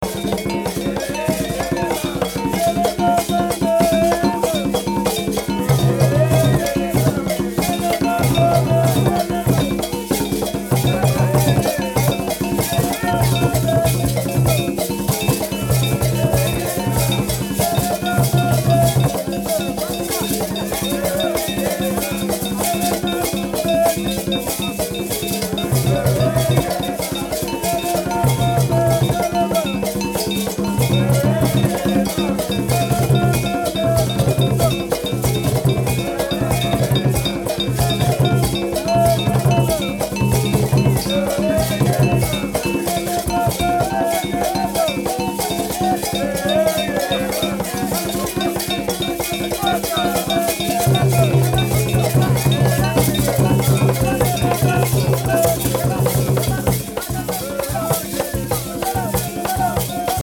Bell
This type of instrument is used in recordings of our sound archives made with the Congolese peoples mentioned hereafter where it appears with the following vernacular names: